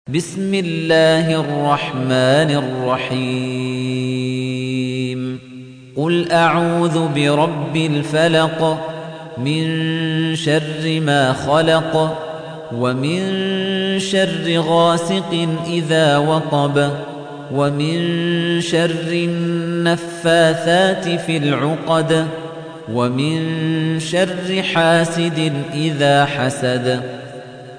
تحميل : 113. سورة الفلق / القارئ خليفة الطنيجي / القرآن الكريم / موقع يا حسين